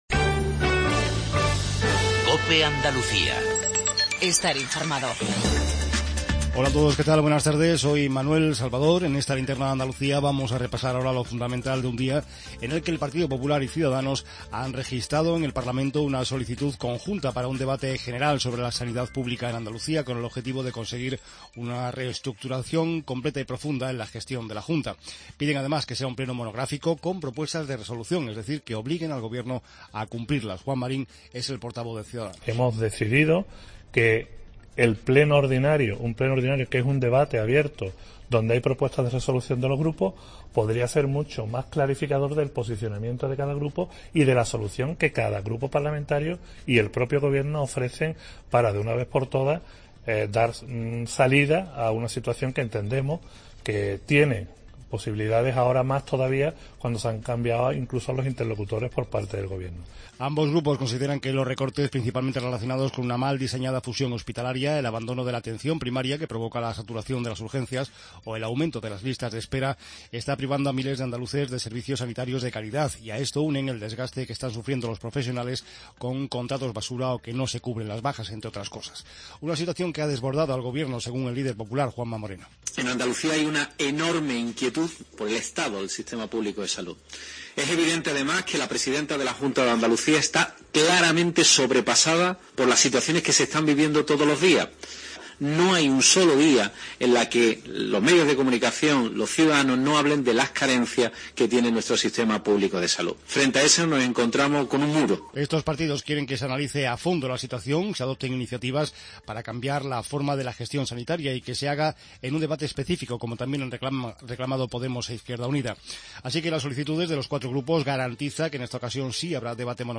INFORMATIVO REGIONAL TARDE